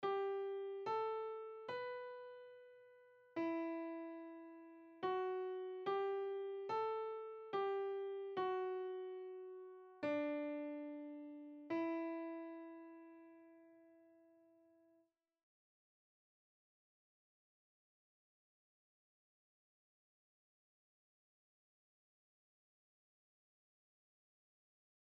Übehilfen für das Erlernen von Liedern